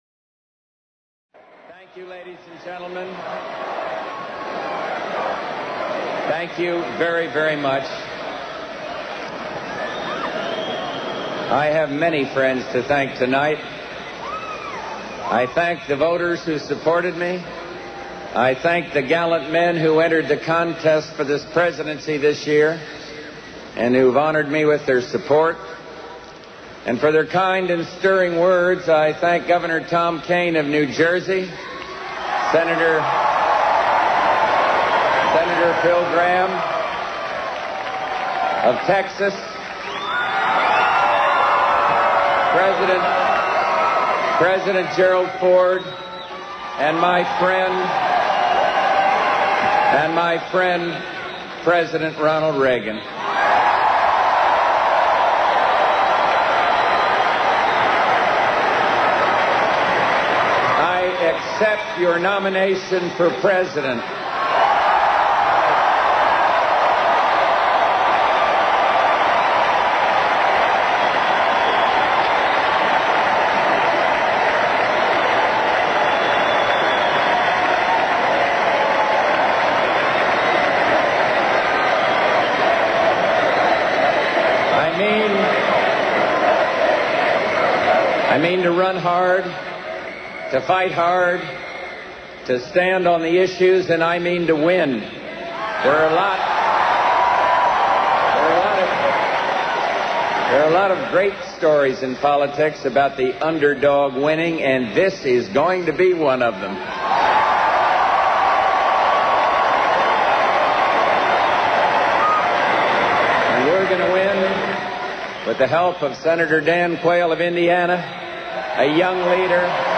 georgehbush1988rnc.mp3